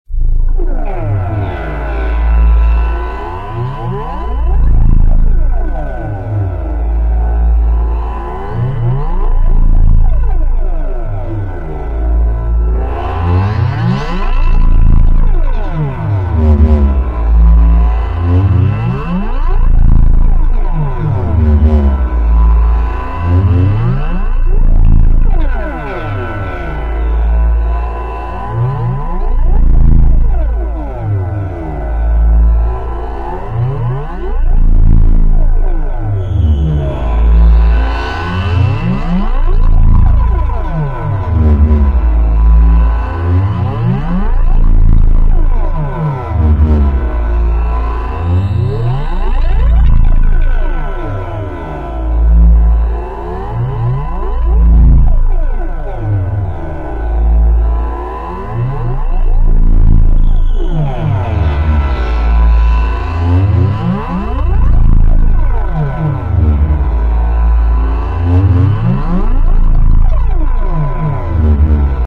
It is designed to make your speakers do weird vibrations and sound like they're about to explode, but it should be safe...I think...(1.18MB)